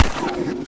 strider_minigun.ogg